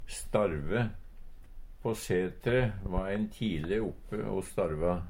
Høyr på uttala Ordklasse: Verb Kategori: Jordbruk og seterbruk Attende til søk